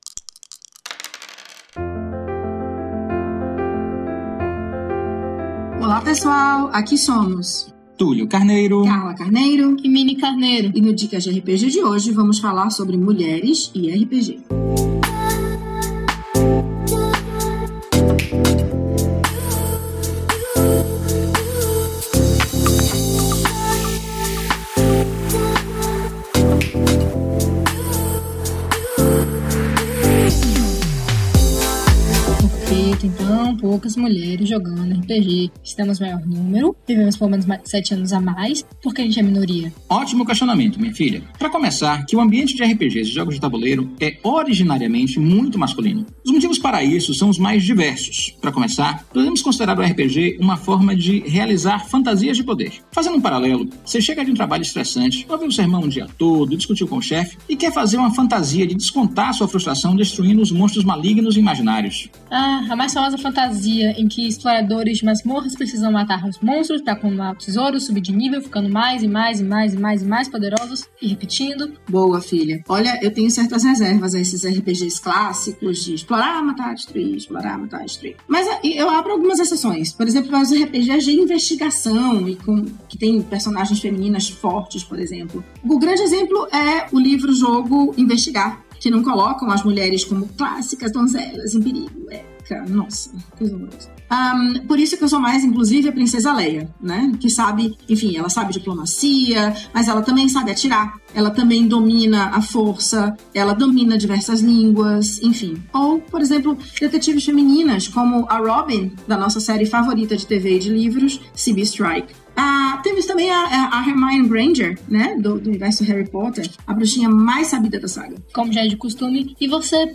Músicas: Music by from Pixabay